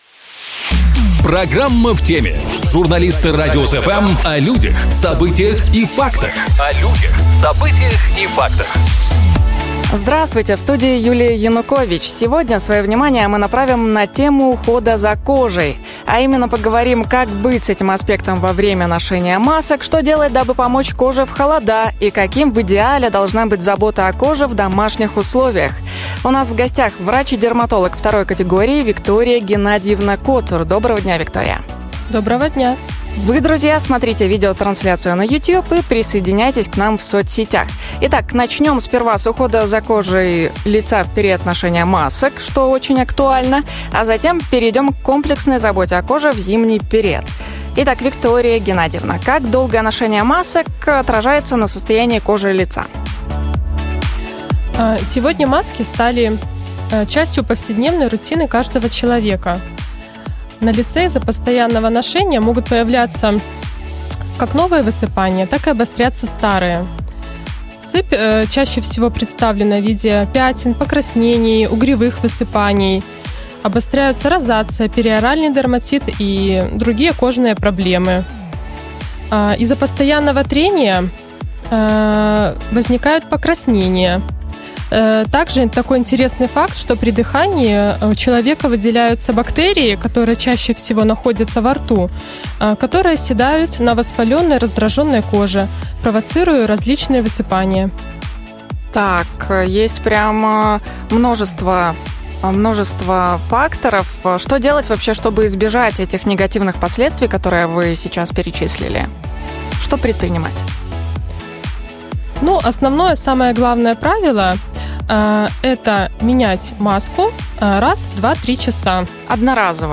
У нас в гостях - врач-дерматолог